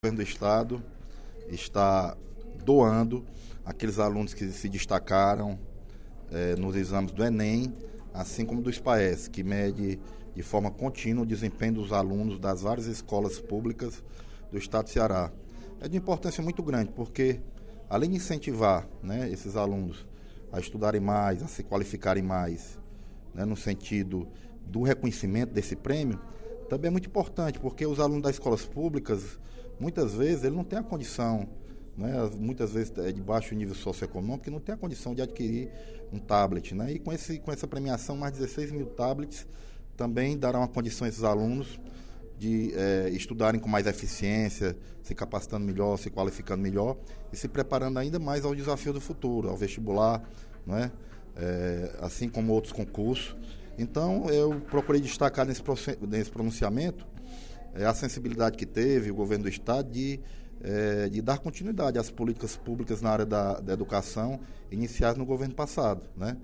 O deputado Leonardo Pinheiro (PP) destacou, durante o primeiro expediente da sessão plenária desta terça-feira (12/07), a solenidade de entrega do Prêmio Aprender pra Valer aos alunos do Ensino Médio da rede estadual que se destacaram pelo bom desempenho acadêmico.